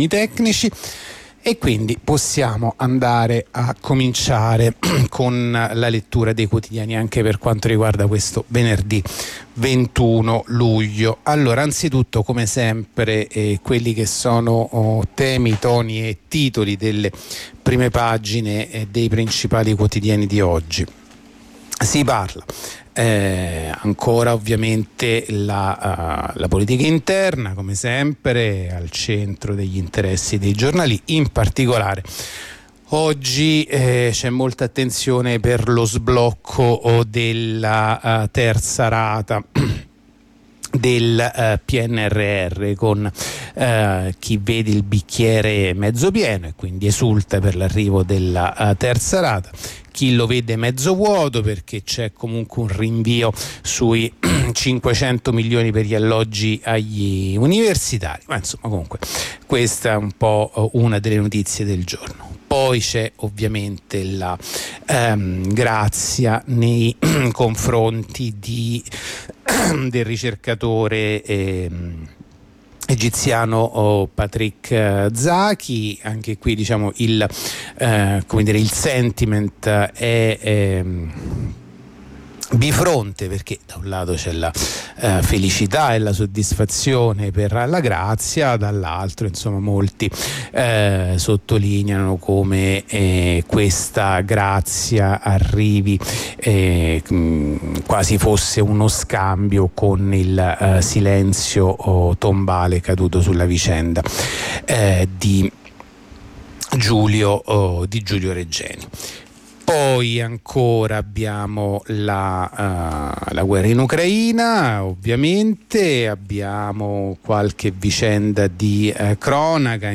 La rassegna stampa di venerdì 21 luglio 2023
La rassegna stampa di radio onda rossa andata in onda venerdì 21 luglio 2023